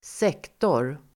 Uttal: [²s'ek:tor]